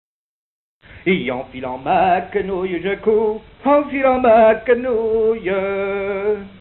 Localisation Saint-Gervais
Genre énumérative
Chanson